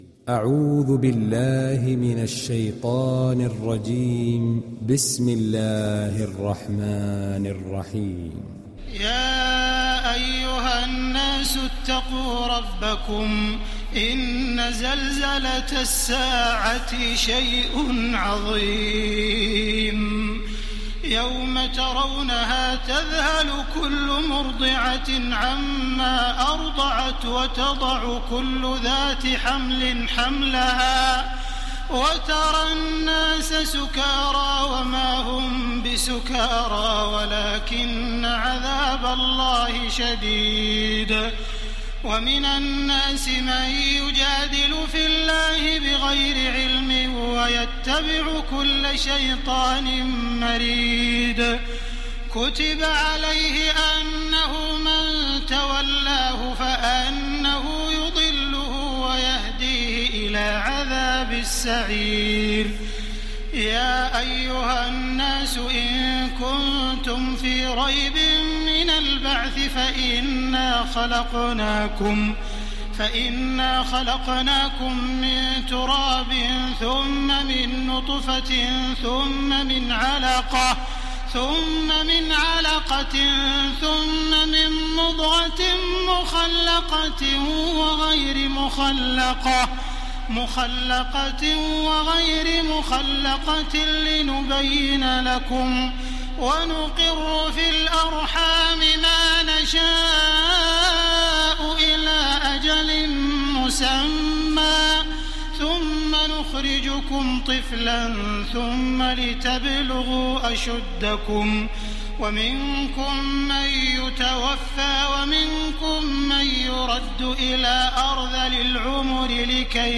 Télécharger Sourate Al Haj Taraweeh Makkah 1430
Moratal